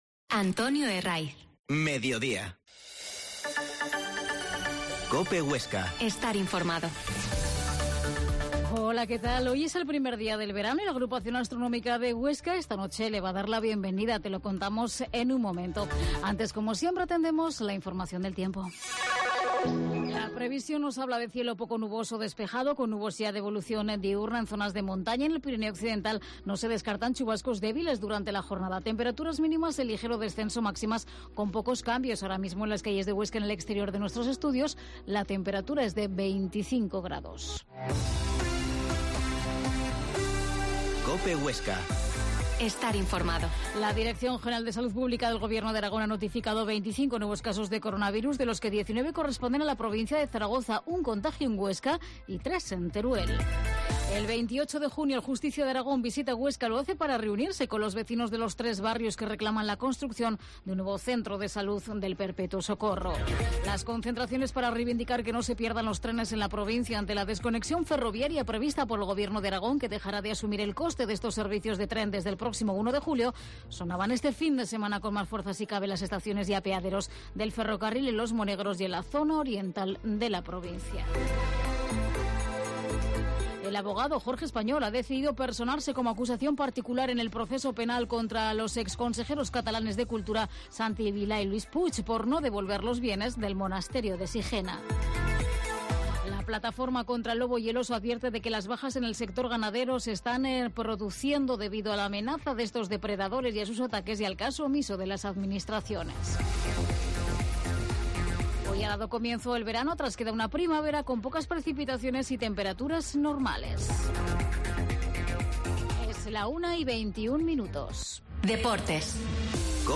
Mediodia en COPE Huesca 13.20H Entrevista al Pte.